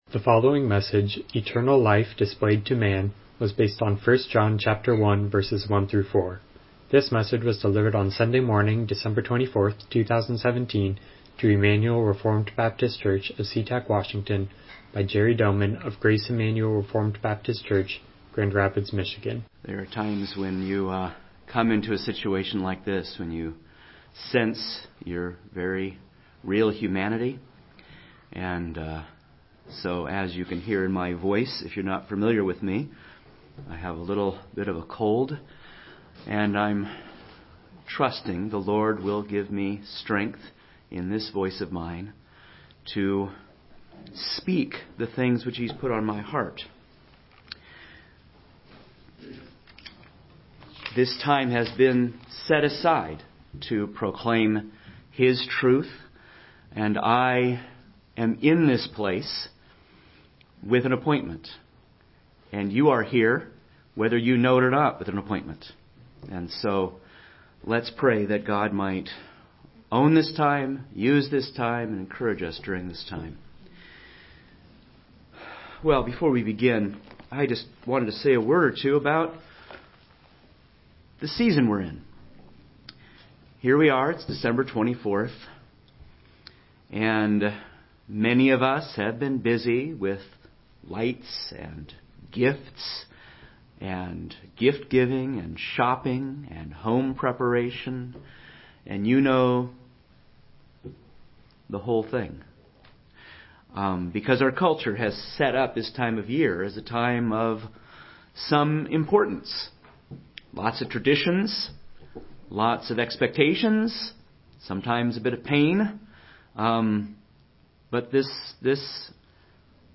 Miscellaneous Passage: 1 John 1:1-4 Service Type: Morning Worship « Who Was/Is Jesus?